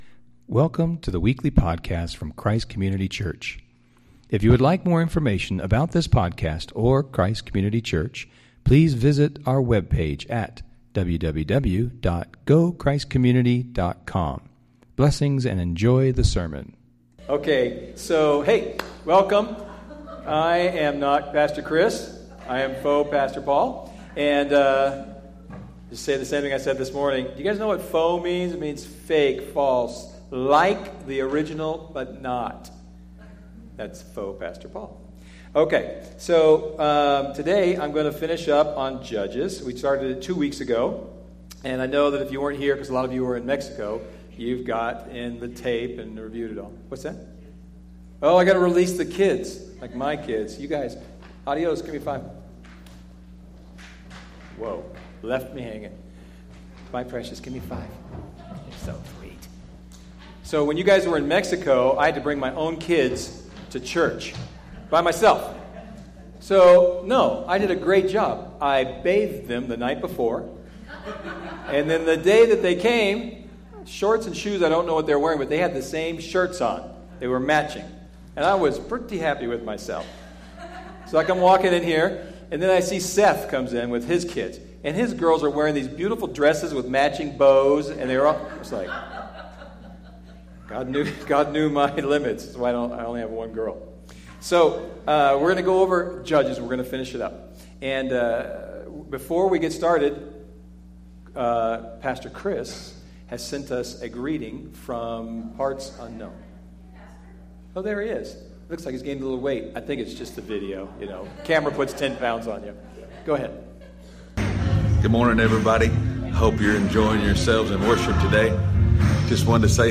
Type: Sunday Morning